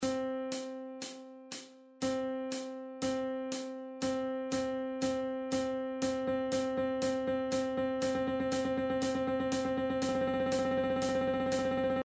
Каждая длительность играет в новом такте на нотном стане, который расположен ниже.